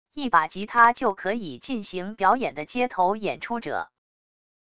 一把吉他就可以进行表演的街头演出者wav音频